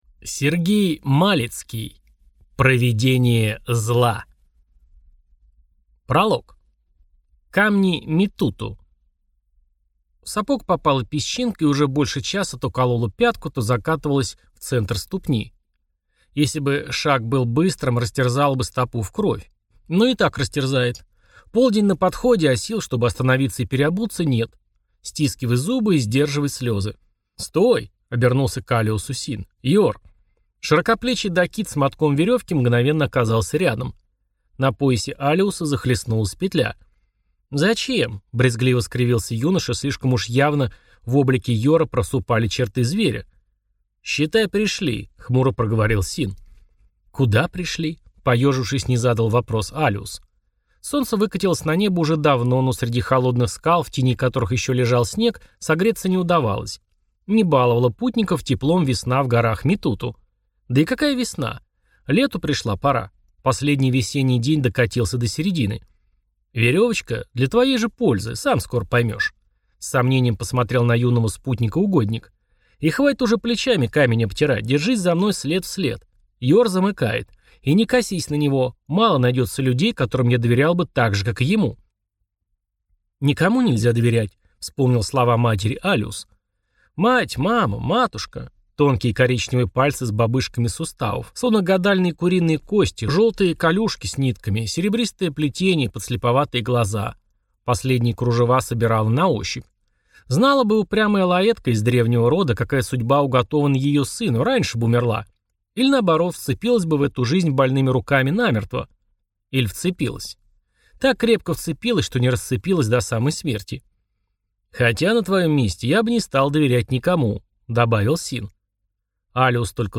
Аудиокнига Провидение зла | Библиотека аудиокниг